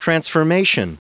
Prononciation du mot transformation en anglais (fichier audio)
Prononciation du mot : transformation